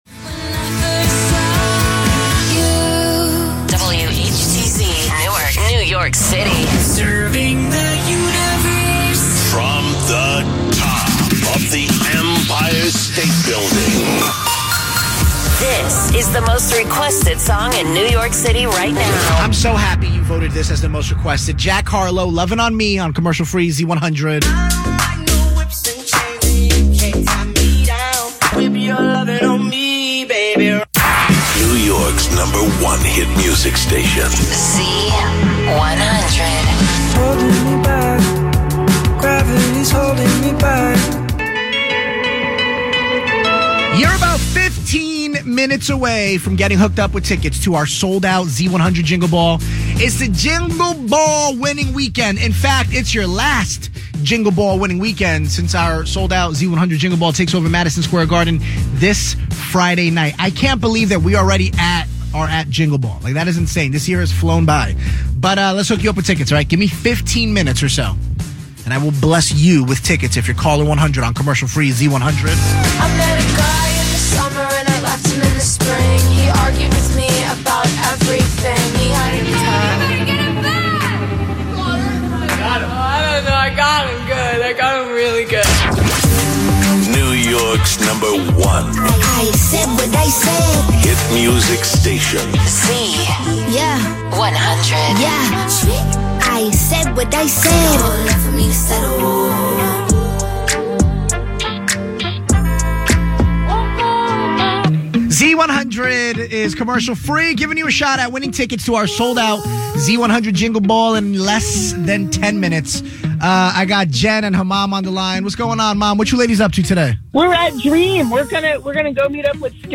Z100 zendt nog steeds de populairste hits van dit moment uit in een vrij hoge rotatie, ‘Cruel Summer’ van Taylor Swift kwam afgelopen week elk uur voorbij, afgewisseld met enkele classics van de afgelopen jaren. Vanwege de hoge rotaties kondigen de deejays de gedraaide nummers nog nauwelijks af of aan. Vaak gaan de spreeks uitsluitend over winacties of de iHeart Radio app.